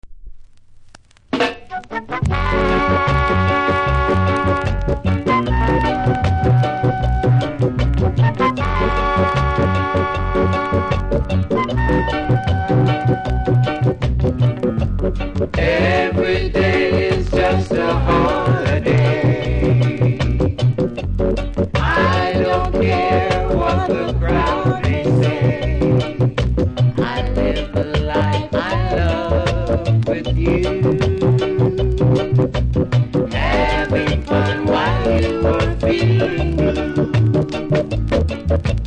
キズ多めノイズもそこそこありますがプレイは問題無いレベル。